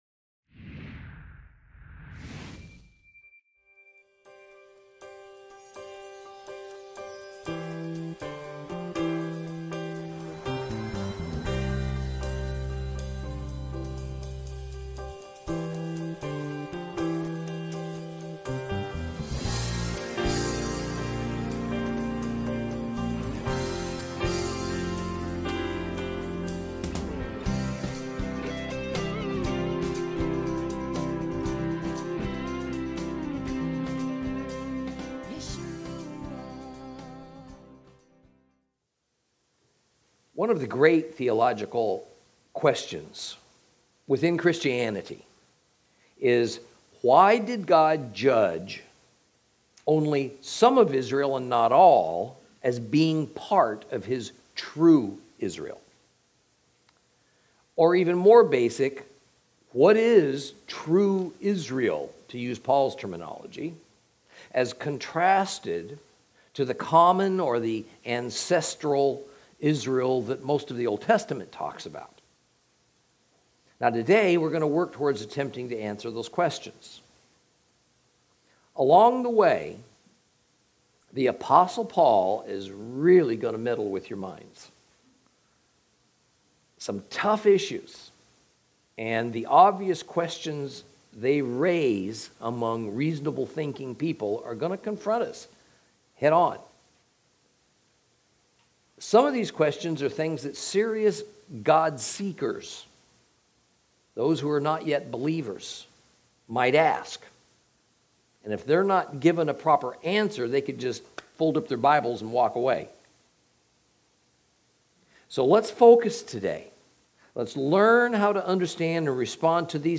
Lesson 21 Ch9 - Torah Class